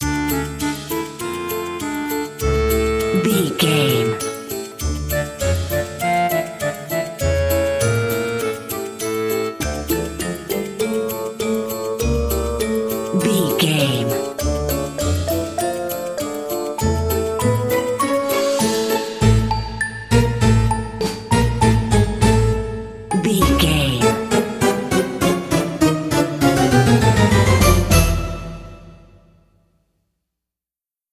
Aeolian/Minor
D
orchestra
harpsichord
silly
circus
goofy
comical
cheerful
perky
Light hearted
quirky